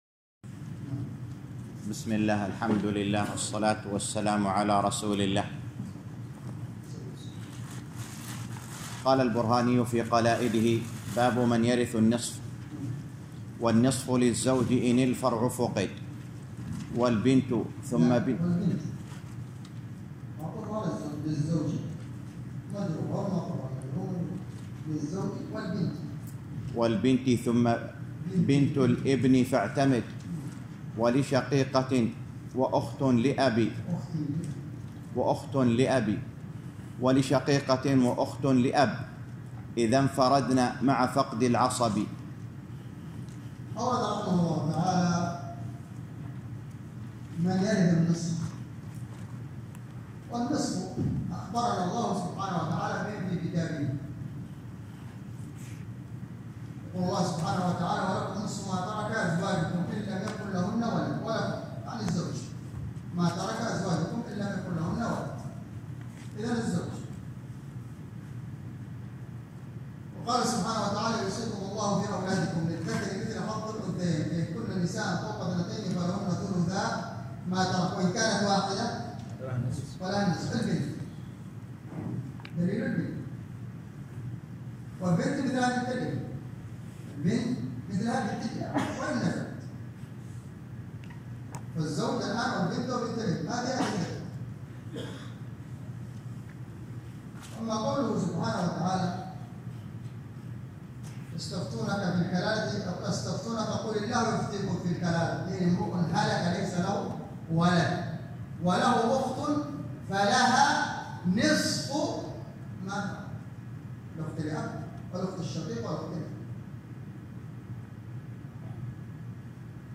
الدرس التاسع - شرح المنظومة البرهانية في الفرائض _ 9